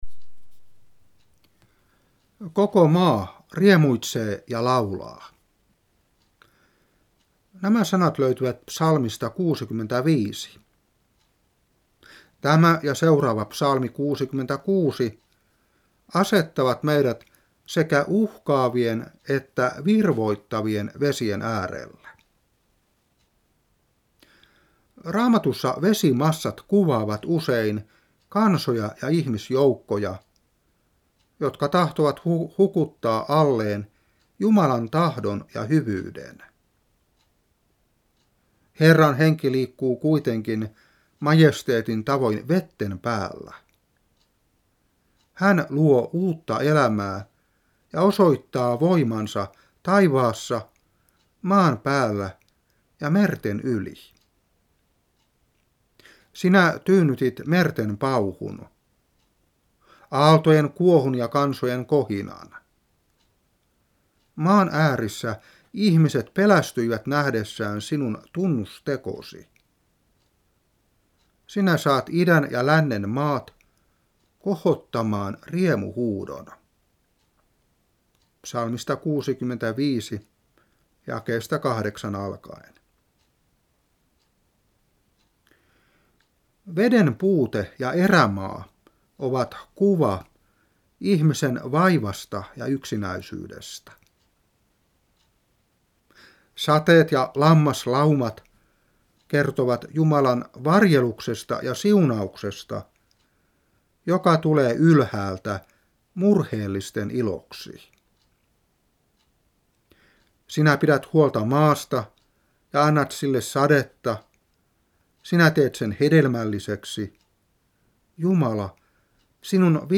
Opetuspuhe 2019-6.